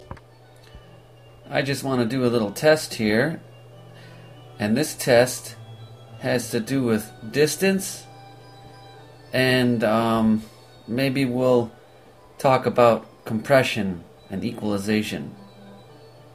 voc 1 (farther)
Notice how much noisier voc 1 becomes.
AUD_voice_far_comp.mp3